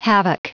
Prononciation du mot havoc en anglais (fichier audio)
Prononciation du mot : havoc